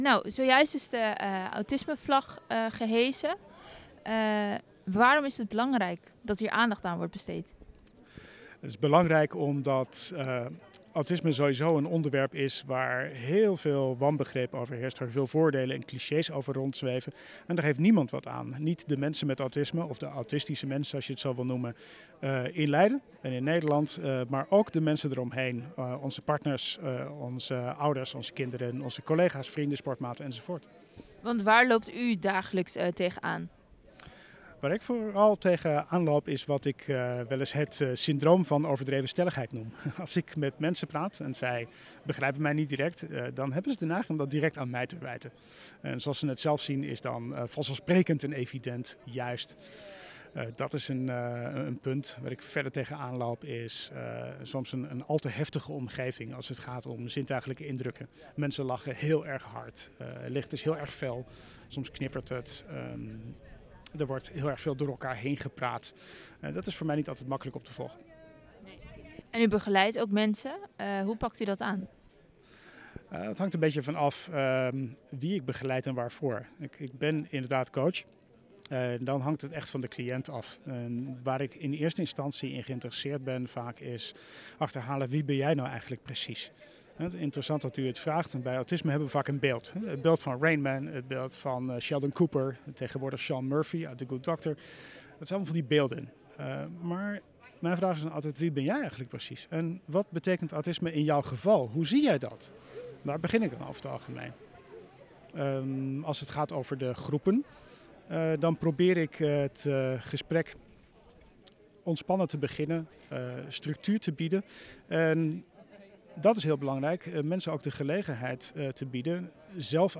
interview-autisme-2.wav